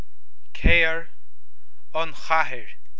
Comhad Fuaime Foghraíochta